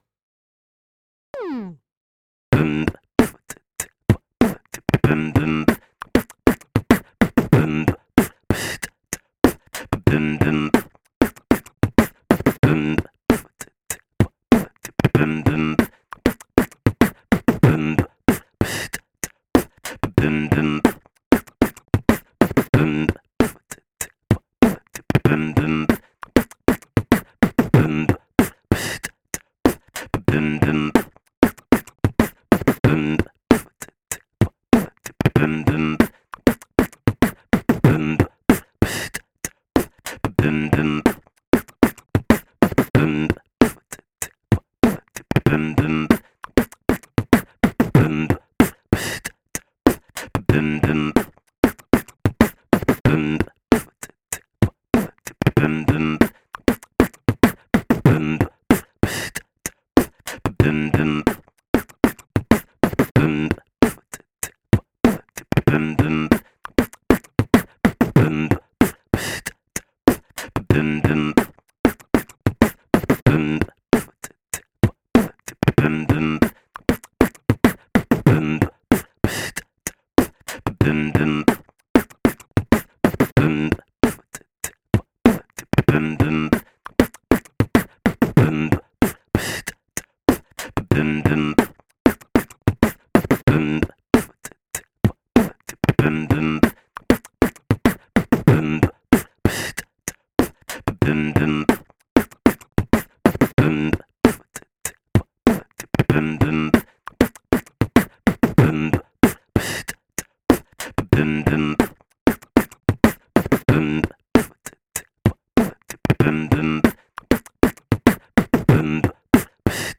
Po prostu włącz, aby poćwiczyć beatbox z bitem do pomocy. Bit jest na tyle prosty, że możesz dodać tam wiele swoich dźwięków, do czego właśnie zachęcam. Również został podzielony na sekcję z bitem i drugą część z samym basem.
loop 2 (4/4, 95bpm, 5:28min, 5.25mb)